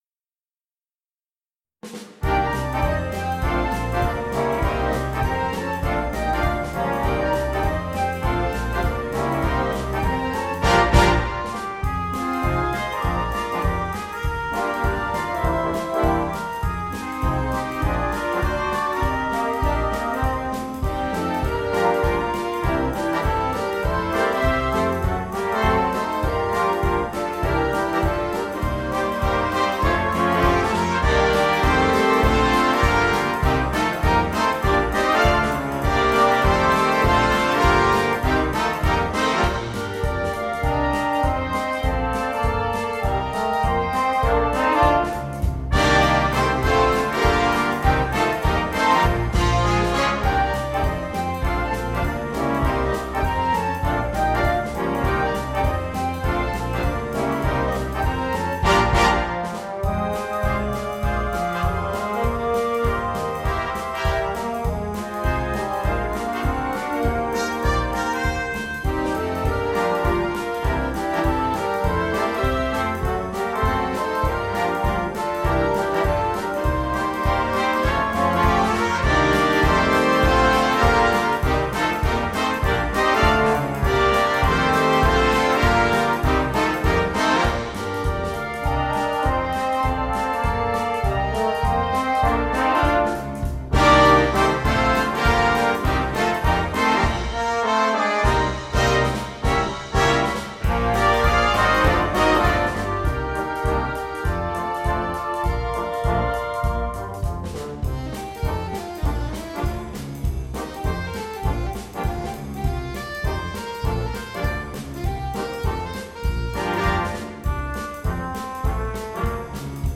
POP & LIGHT MUSIC
Noten für Blasorchester.